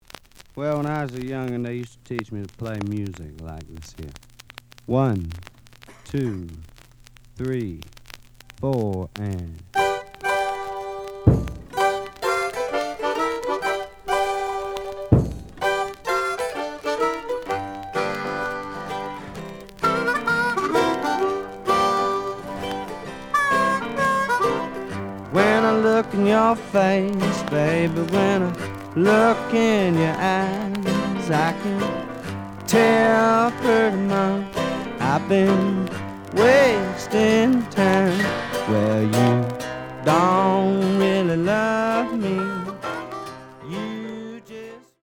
試聴は実際のレコードから録音しています。
●Genre: Rock / Pop
●Record Grading: VG~VG+ (両面のラベルに若干のダメージ。盤に若干の歪み。プレイOK。)